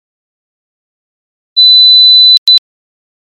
(Pour écouter les sons à différentes fréquences ci-dessous, il suffit de cliquer sur celui que vous souhaitez entendre)
4 kHz] [8 kHz] [